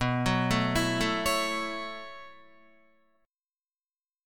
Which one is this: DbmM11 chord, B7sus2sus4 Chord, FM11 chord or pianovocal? B7sus2sus4 Chord